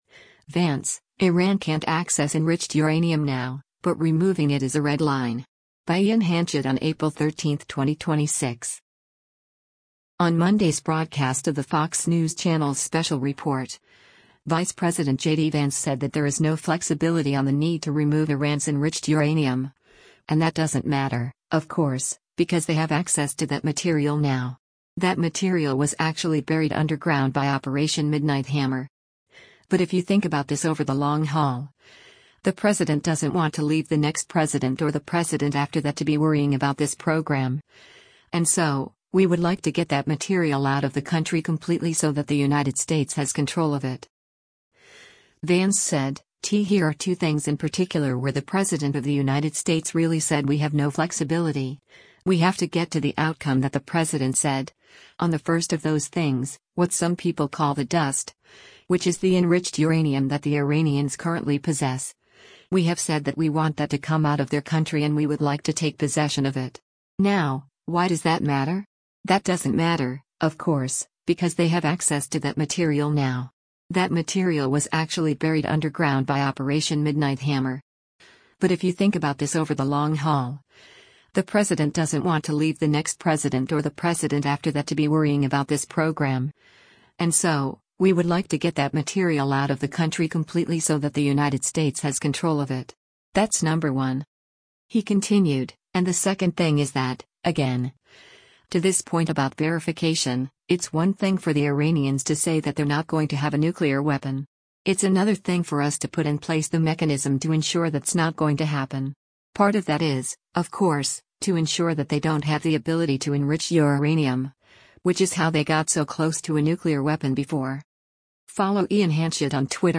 On Monday’s broadcast of the Fox News Channel’s “Special Report,” Vice President JD Vance said that there is “no flexibility” on the need to remove Iran’s enriched uranium, and “That doesn’t matter, of course, because they have access to that material now.